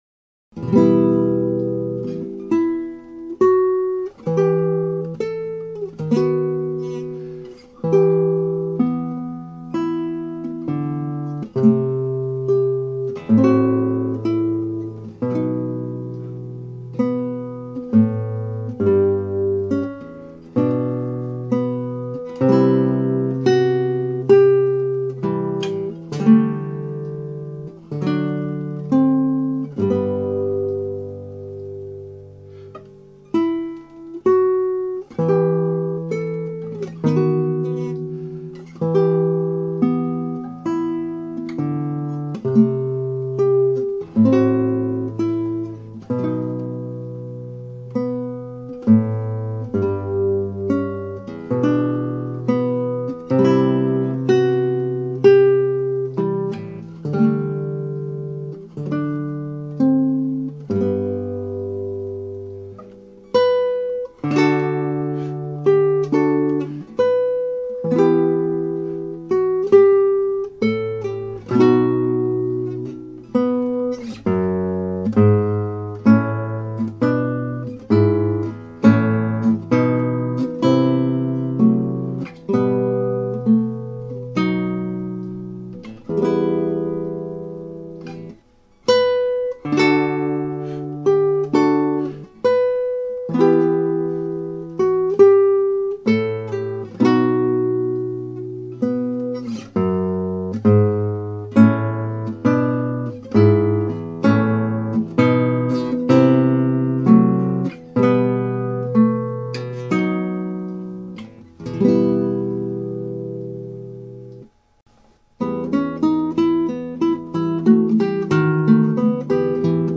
(アマチュアのクラシックギター演奏です [Guitar amatuer play] )
ここで録音したのはアンドレス・セゴビアの編曲(ショット、1939)をベースにしています。
最後のテーマはピカルディ3度で終わっていますがこれが正しいのか疑ってしまうほど出来の悪い楽譜です。
私の演奏のほうもかなり悪いですが。